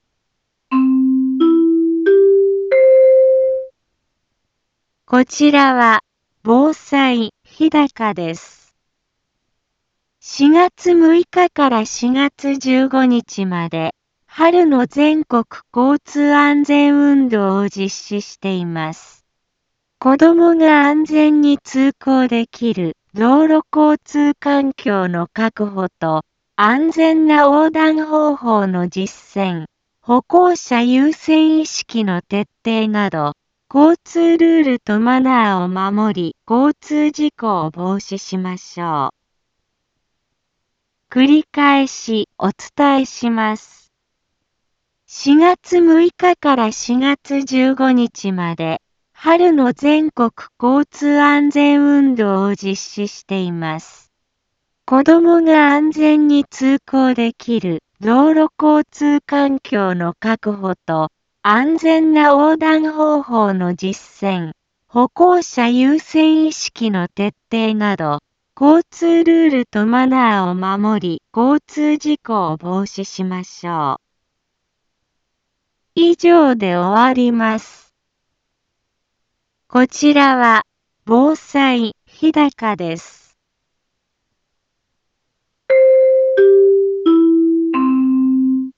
Back Home 一般放送情報 音声放送 再生 一般放送情報 登録日時：2025-04-07 15:03:11 タイトル：交通安全のお知らせ インフォメーション： こちらは、防災日高です。 4月6日から4月15日まで「春の全国交通安全運動」を実施しています。